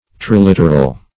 Search Result for " triliteral" : The Collaborative International Dictionary of English v.0.48: Triliteral \Tri*lit"er*al\, a. [Pref. tri- + literal.] Consisting of three letters; trigrammic; as, a triliteral root or word.